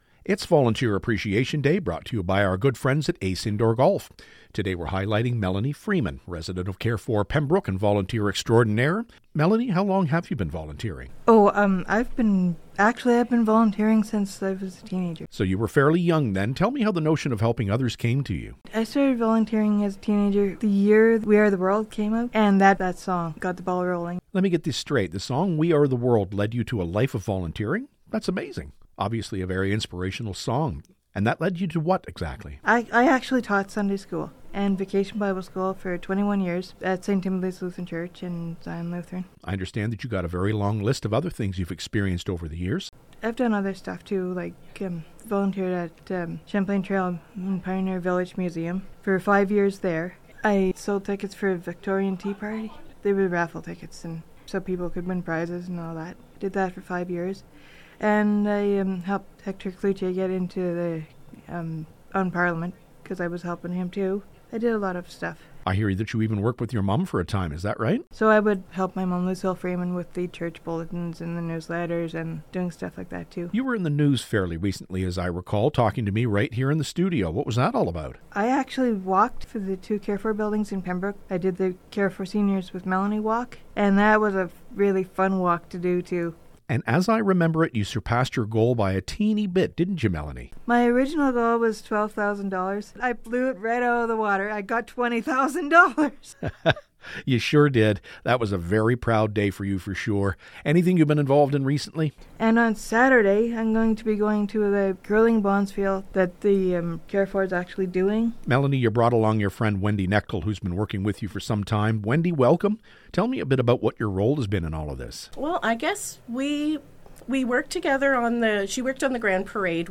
stopped by the myFM studio to talk about volunteerism.